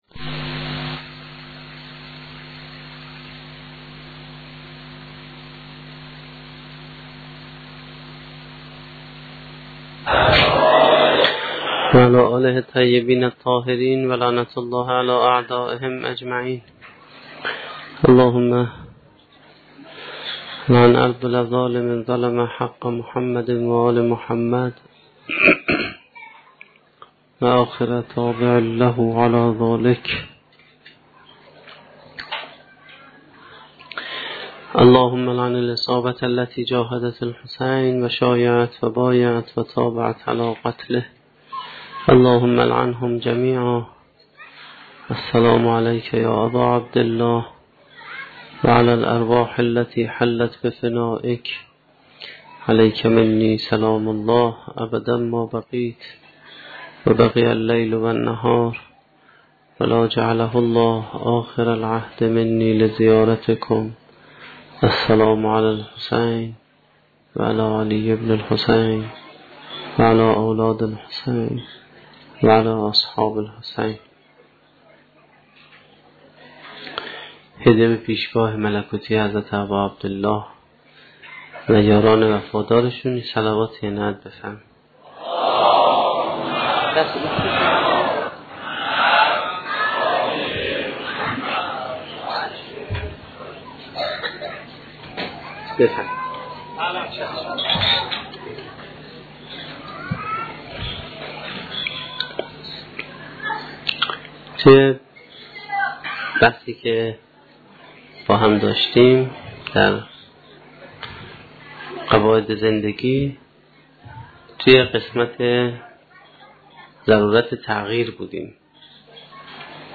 سخنرانی نهمین شب دهه محرم1435-1392